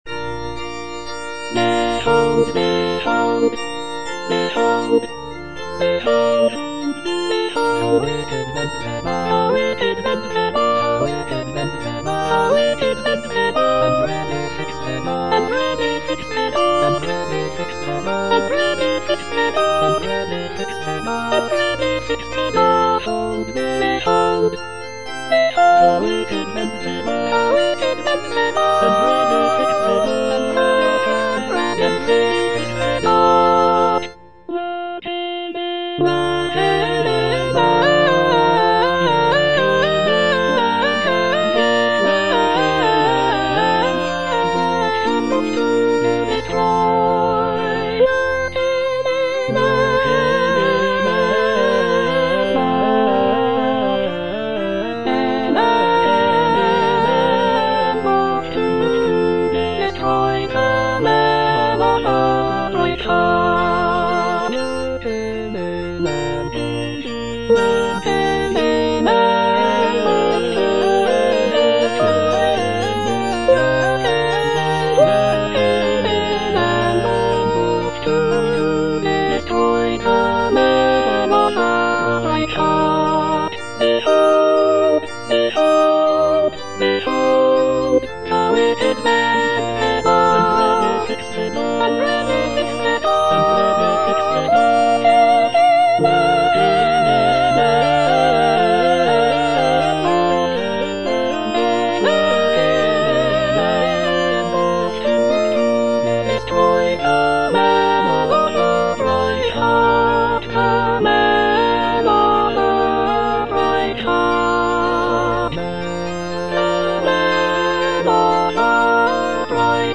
Choralplayer playing In the Lord put I my trust - Chandos anthem no. 2 (SATB version) HWV247 by G.F. Händel (arr. D. Cranmer) based on the edition Novello Publishing Ltd. NOV060137
G.F. HÄNDEL - IN THE LORD PUT I MY TRUST HWV247 (SATB VERSION) Behold the wicked bend their bow - Soprano (Emphasised voice and other voices) Ads stop: auto-stop